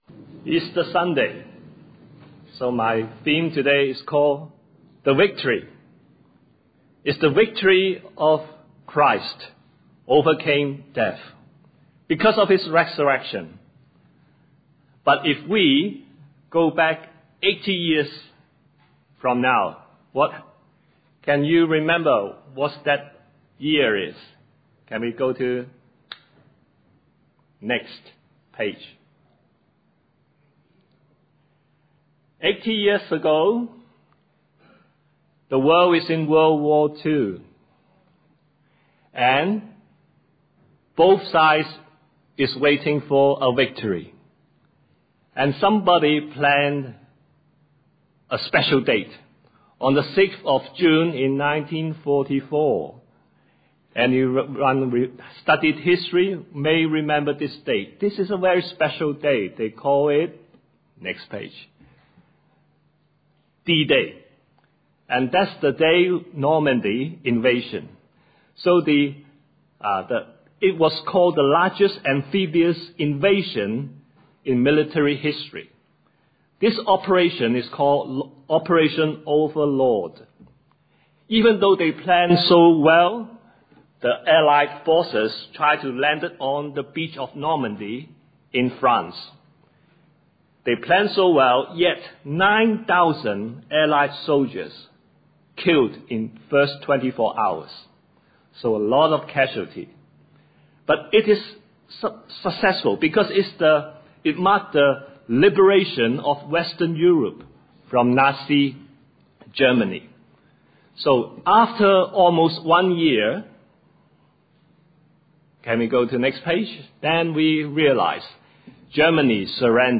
Scripture reference: Acts 2:22-24 A gospel message about the victory of Christ in His Cross and His Resurrection.